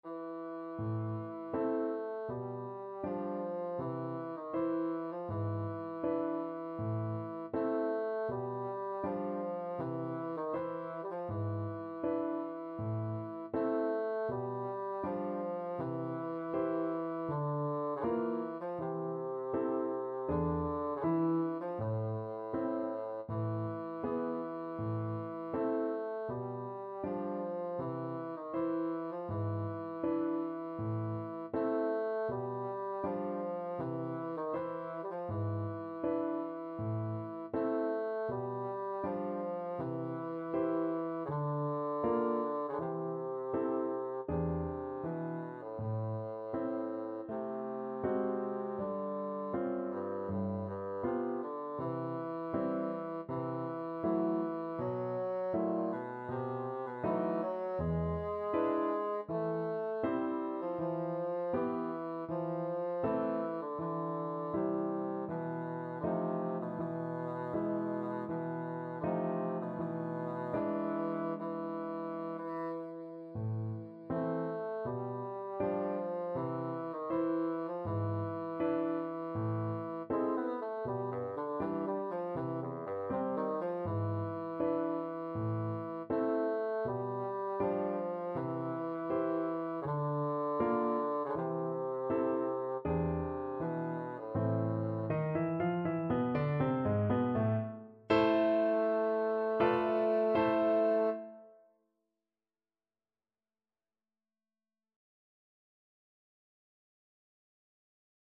Classical Chopin, Frédéric Nocturne Op.55 No.1 Bassoon version
A minor (Sounding Pitch) (View more A minor Music for Bassoon )
4/4 (View more 4/4 Music)
Andante = c.80
Classical (View more Classical Bassoon Music)